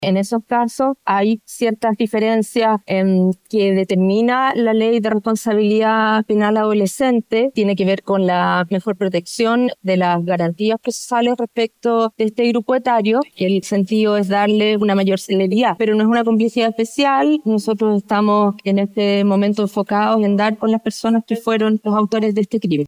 Al respecto, la fiscal regional de Valparaíso, Claudia Perivancich, indicó que se emitió la orden de detención en contra de los involucrados, ambos menores de edad, quienes ya fueron identificados.